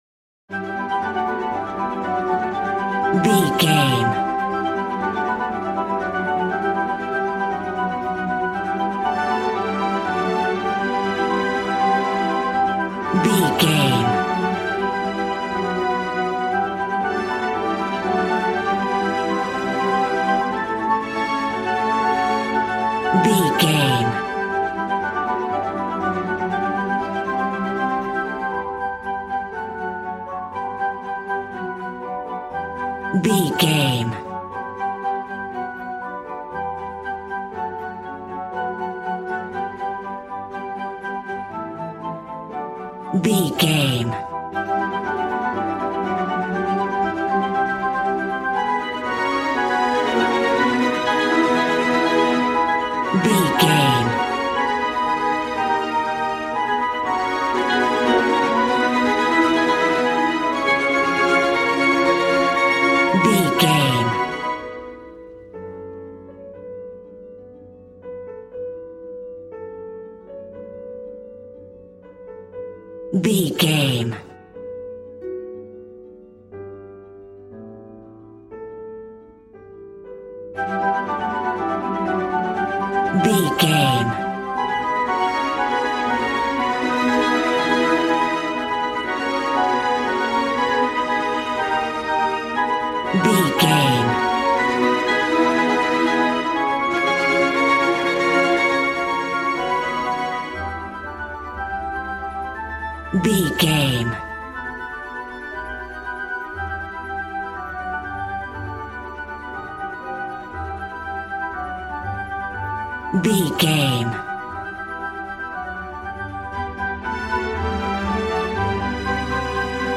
Regal and romantic, a classy piece of classical music.
Ionian/Major
regal
strings
violin
brass